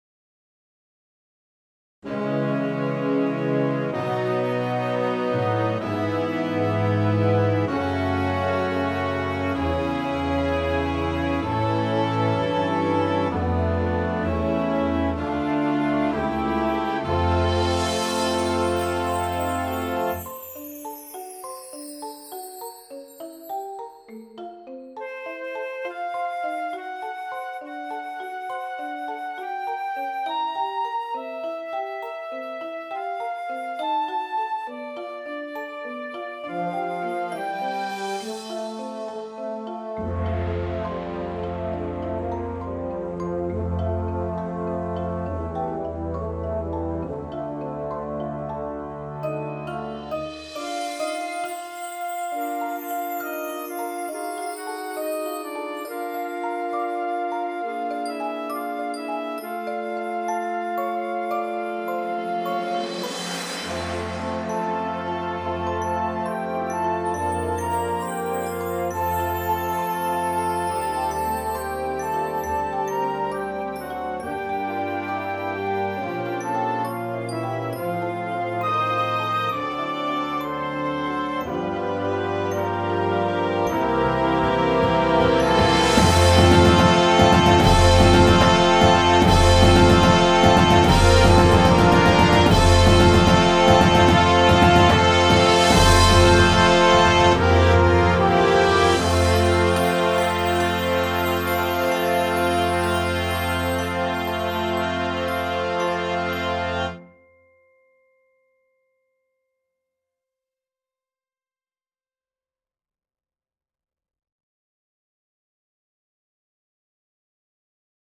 • Trombone 1, 2
• Tuba
• Snare Drum